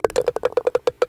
Golf_Ball_Goes_In_Start.ogg